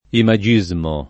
[ ima J&@ mo ]